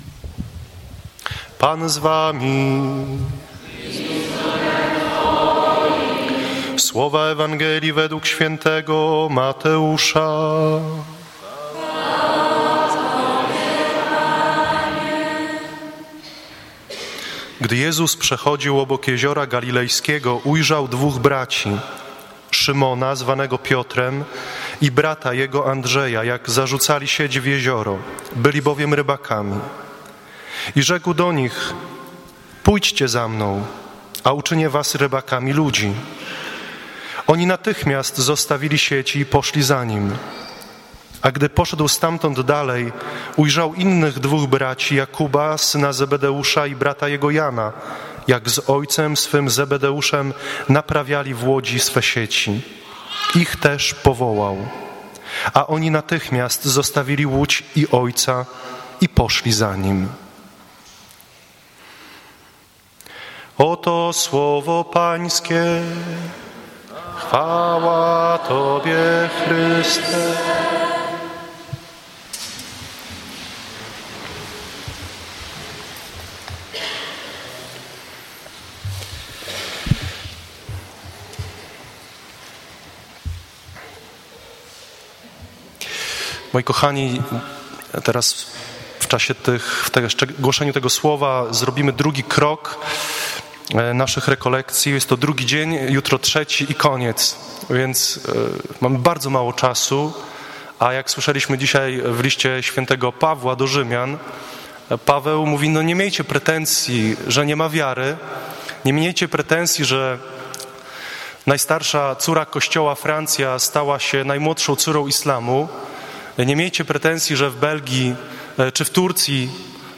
Kazanie Dzień I z 29.11.2015. godz. 17.00 Kazanie Dzień II z 30.11.2015. godz. 18.30 Kazanie Dzień III z 01.12.2015 godz. 20.00